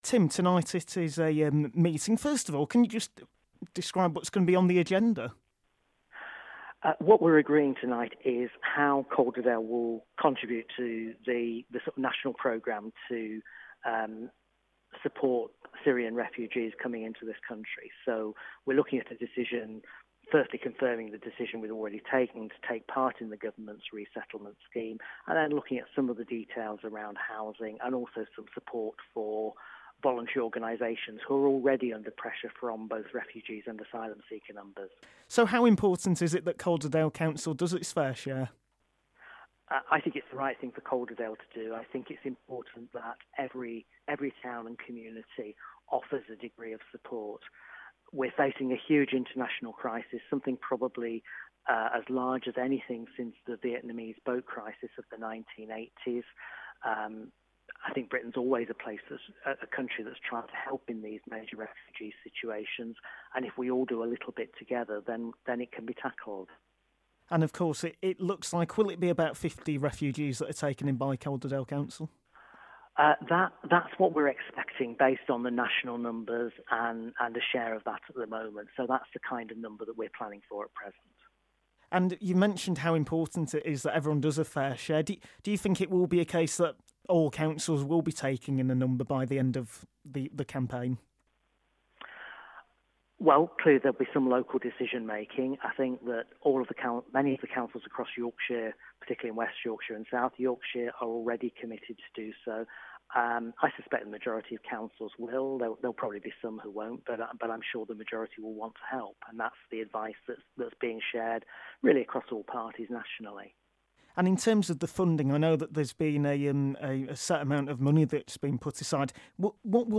Calderdale Council leader Tim Swift talks about refugee plan